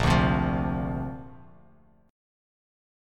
A#add9 chord